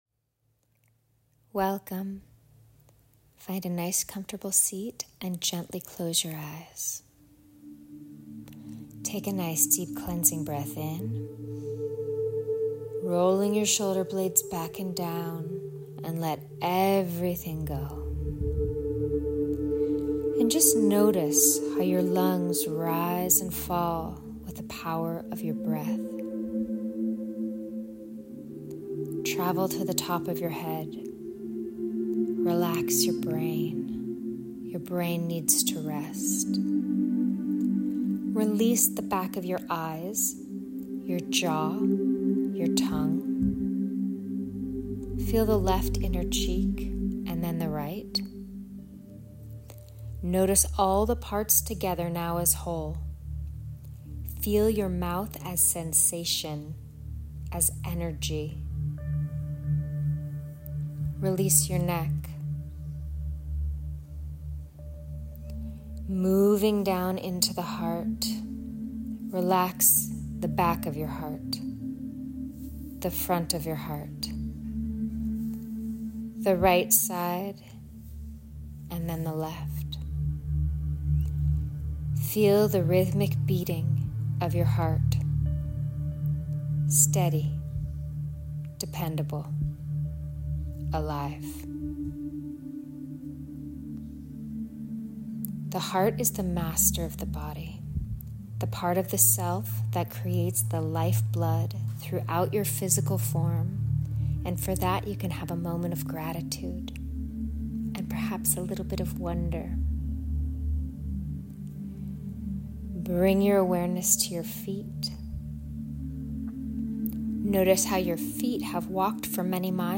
Body-Talk-Meditation.mp3